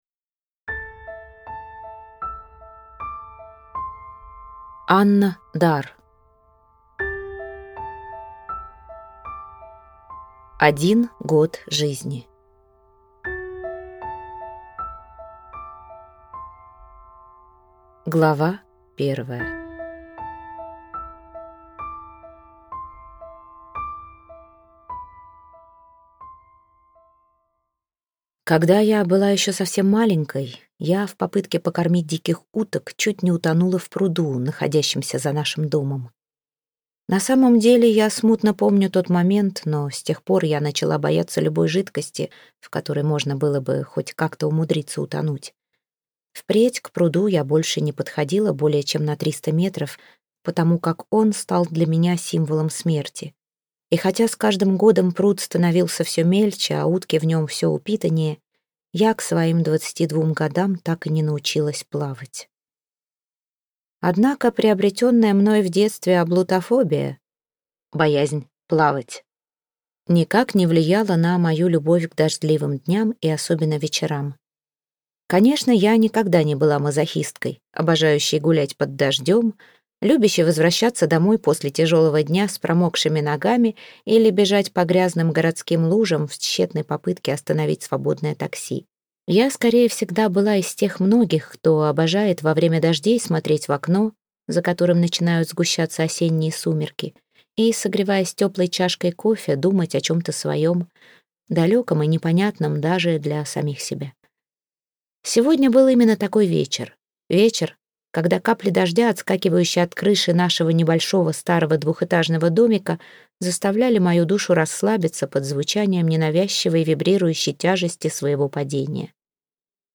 Аудиокнига Один год жизни - купить, скачать и слушать онлайн | КнигоПоиск